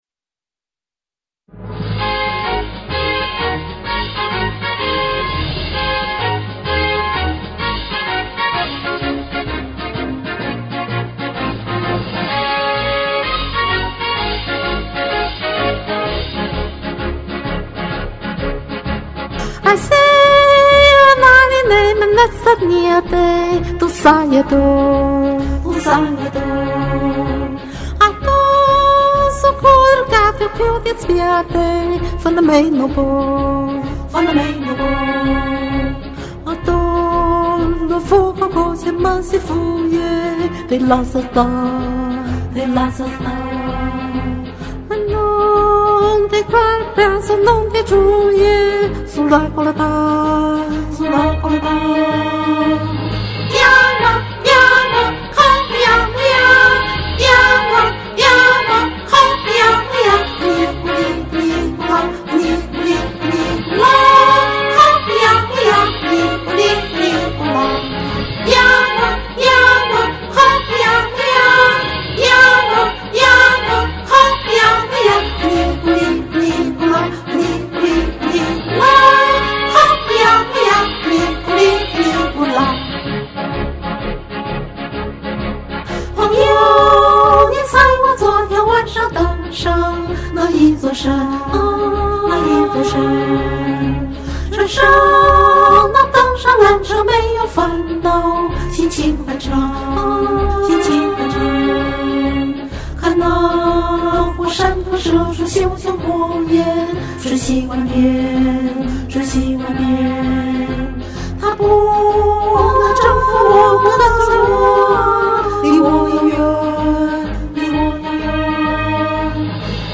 这是一首意大利艺术歌曲。